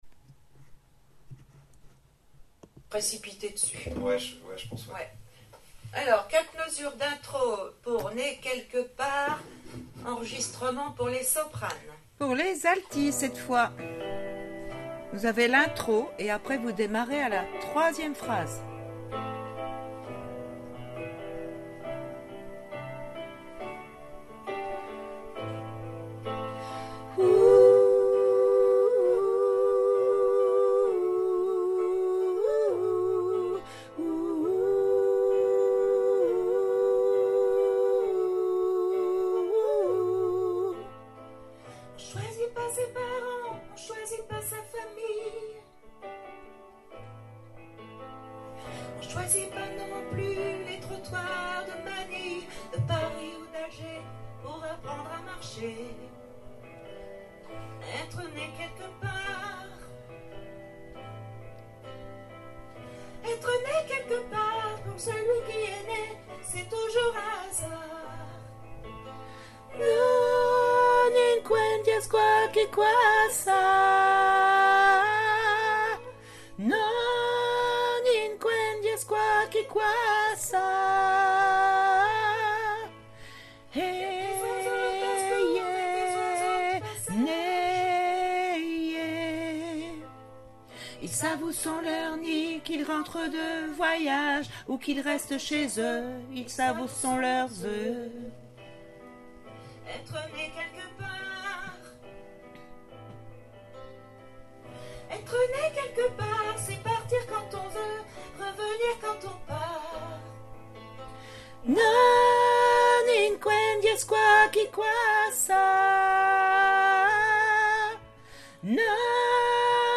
Né piano Alti